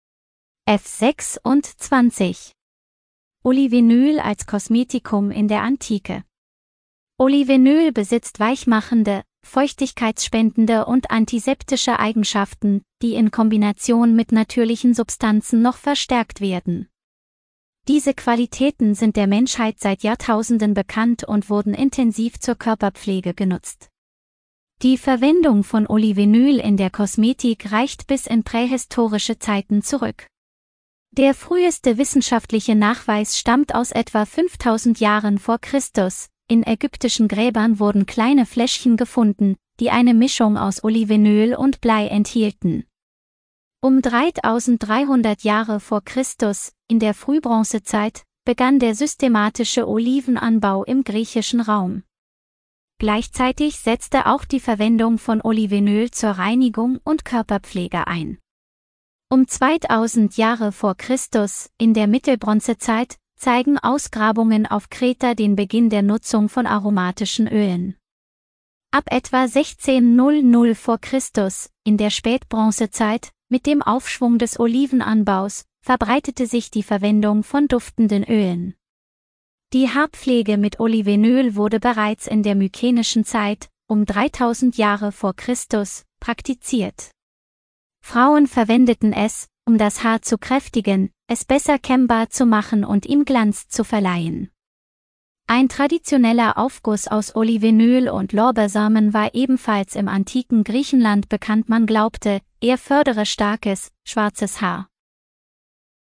Geführte Audio-Tour